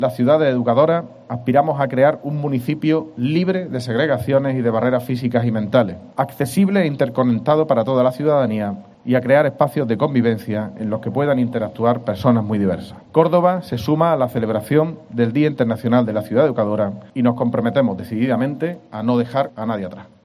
El alcalde de Córdoba, José María Bellido, ha leído este martes en las puertas del Ayuntamiento el comunicado por la celebración este 30 de noviembre del Día Internacional de la Ciudad Educadora, un año después del 30 aniversario de la adhesión de la ciudad a la Carta de Ciudades Educadoras, bajo el lema 'Córdoba, una ciudad que no deja a nadie atrás'.